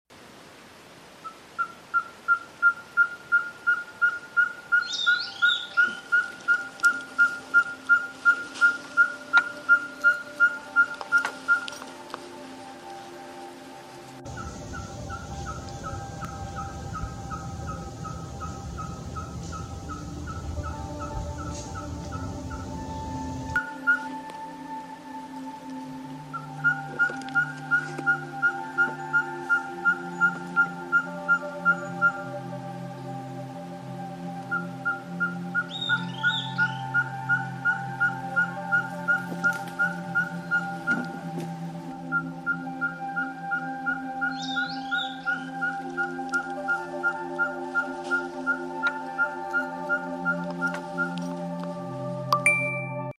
Audio de canto de la aurora